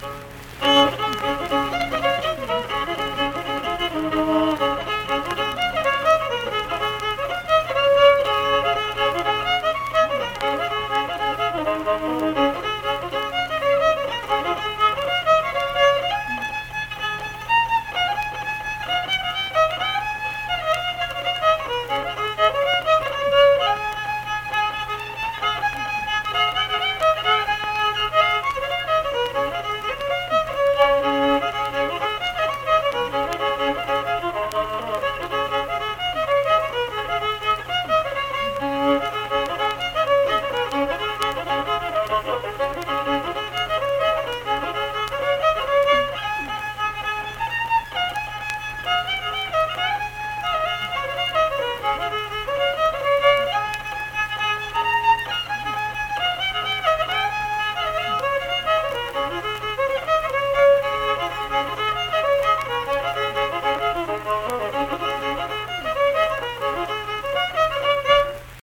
Unaccompanied fiddle music performance
Instrumental Music